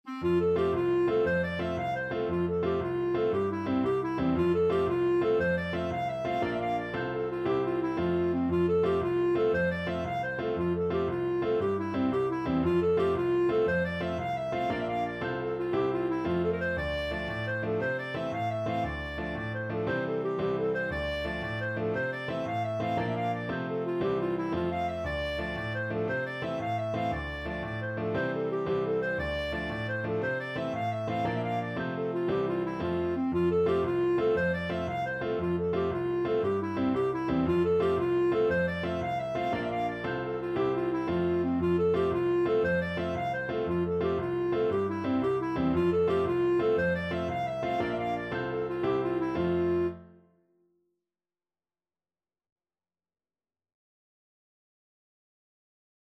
Clarinet
F major (Sounding Pitch) G major (Clarinet in Bb) (View more F major Music for Clarinet )
With energy .=c.116
6/8 (View more 6/8 Music)
Irish